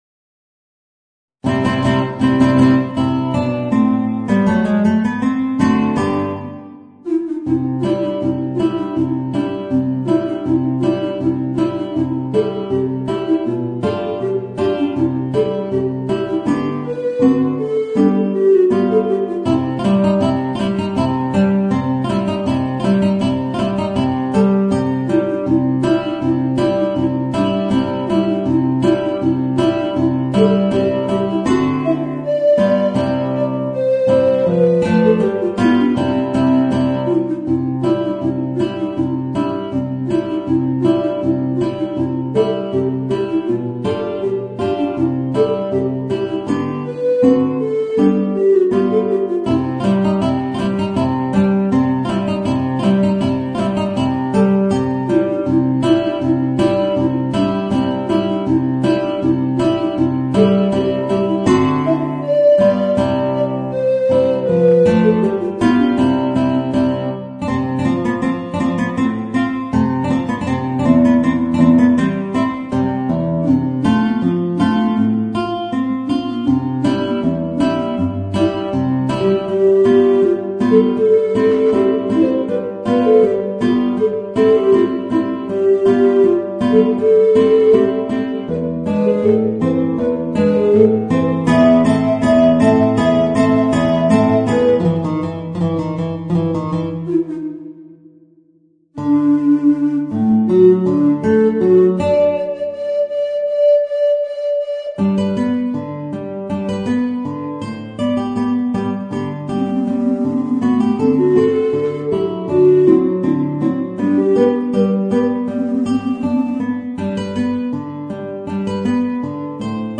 Voicing: Guitar and Bass Recorder